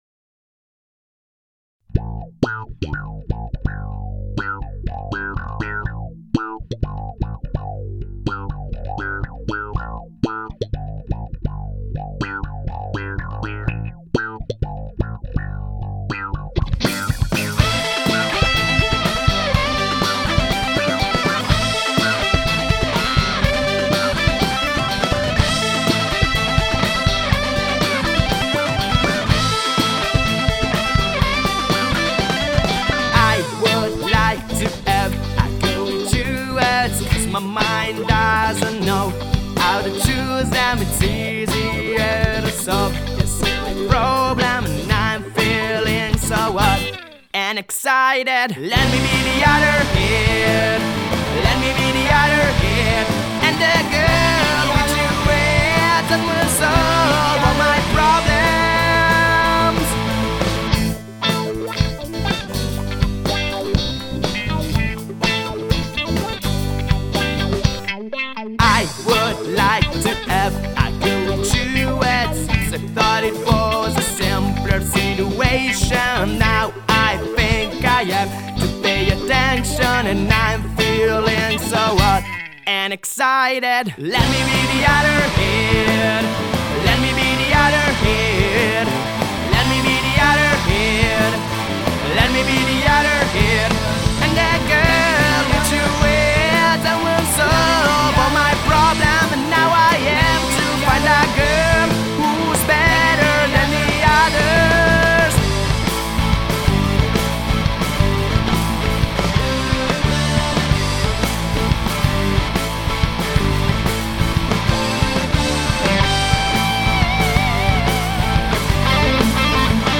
Genere: Alternative Rock
tastiera
chitarra
batteria
basso
chitarra/voce